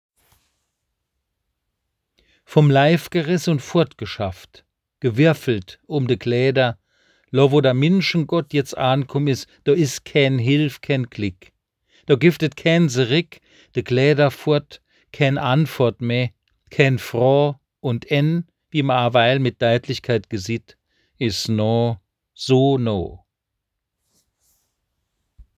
Es enth�lt eine Tonversion der obenstehenden moselfr�nkischen Kreuzwegbetrachtung Sie k�nnen es unter diesem Link abrufen.